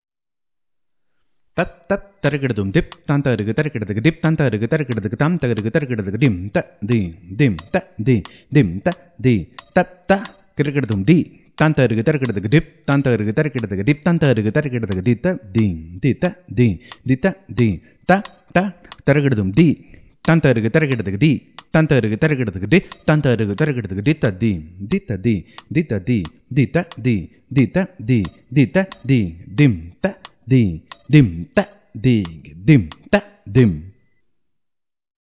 This is a mukthayam of 48 beats, which is a combination of both chaturashra nade and trishra nade.
Konnakol